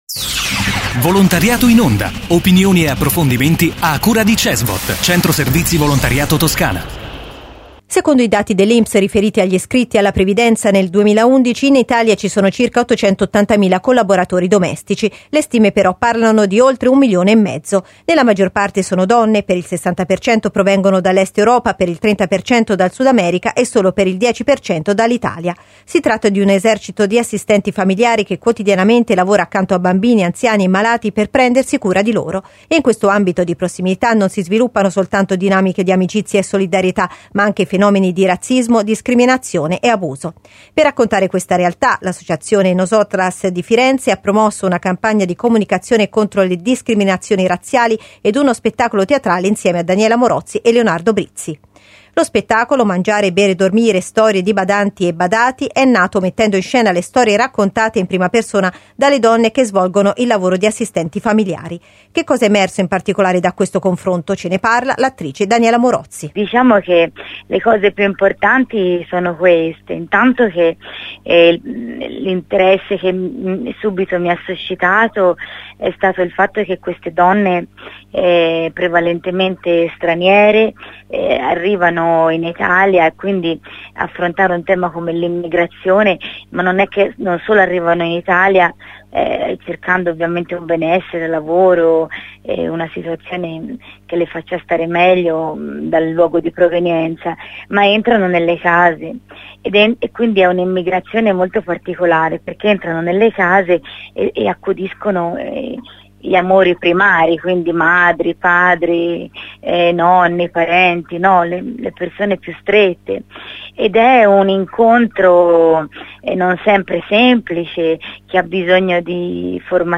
Intervista all'attrice Daniela Morozzi